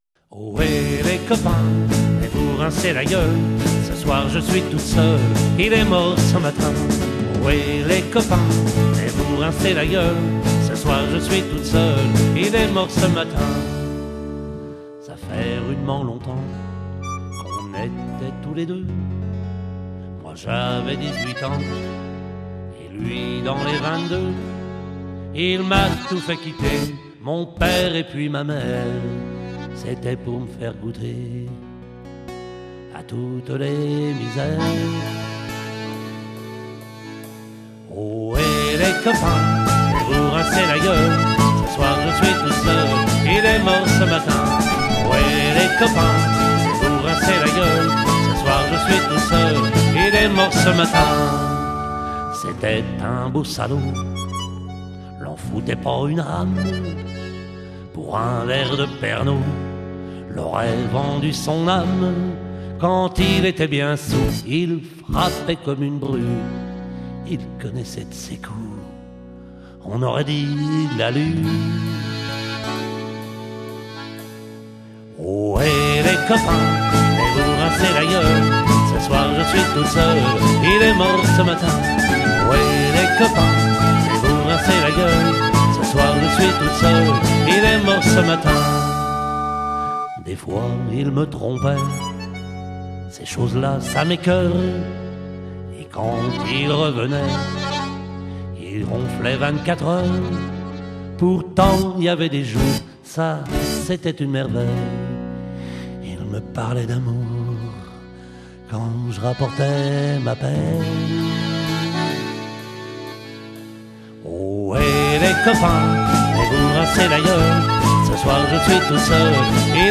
Maquette réalisée en Octobre 2005
aux studios du Micro-Bleu - RUCA (Côtes du Nord)
batterie
chant, guitare acoustique
guitare basse
whistles
accordéon diatonique